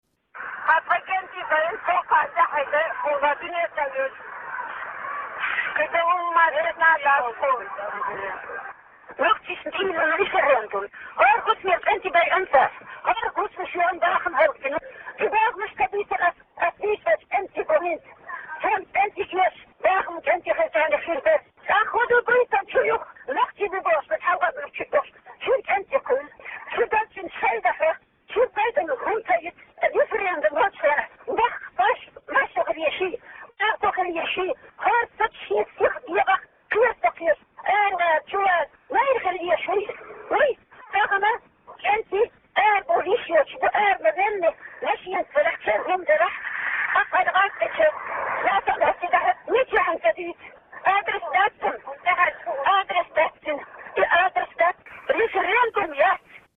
Embed share Нохчийчохь референдум хуьлучу дийнера аьзнаш гIирс: Маршо Радио Embed share The code has been copied to your clipboard.